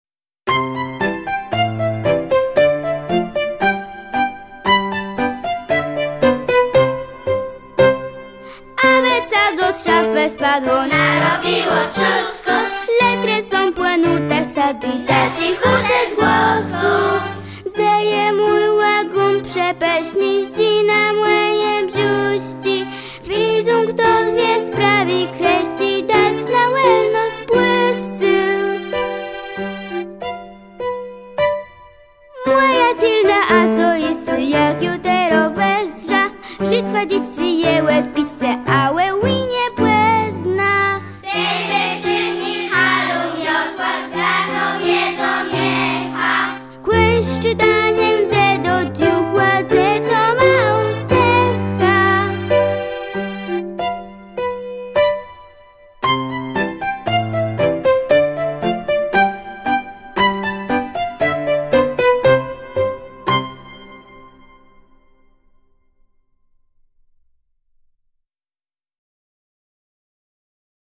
Nagranie wykonania utworu tytuł